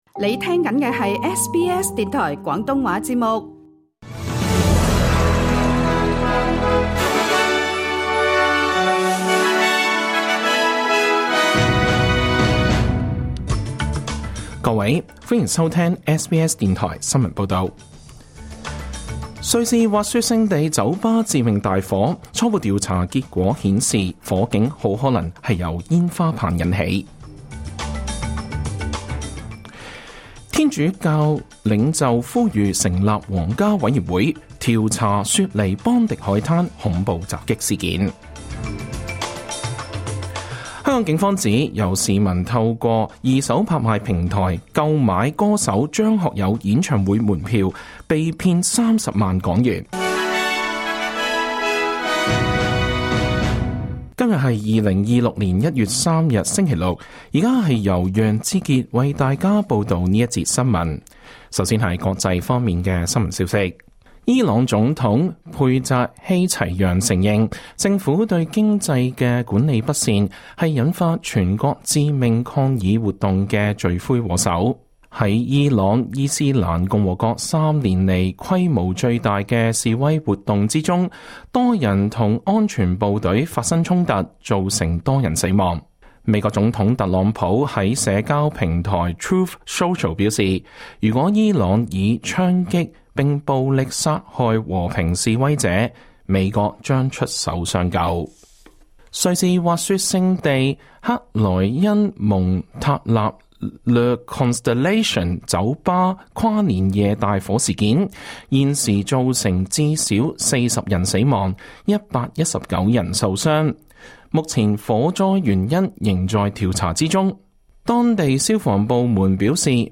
SBS廣東話新聞報道